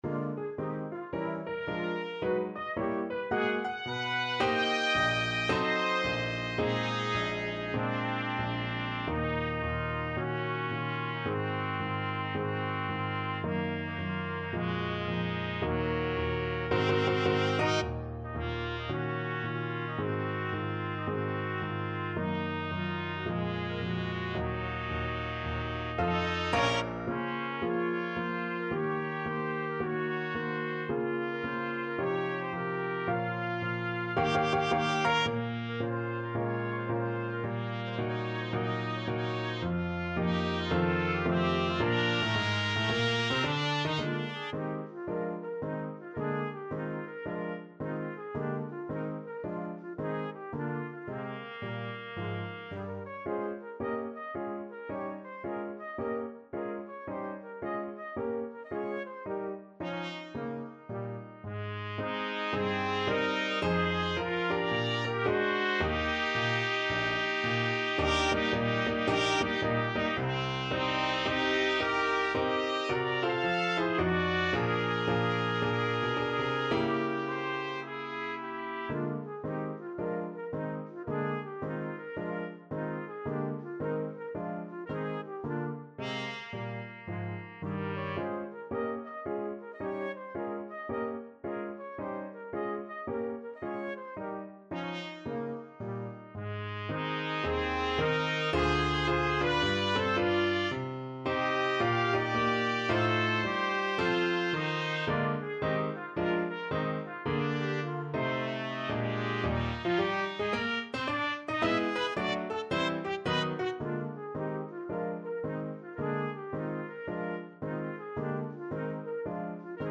4/4 (View more 4/4 Music)
Moderato =110 swung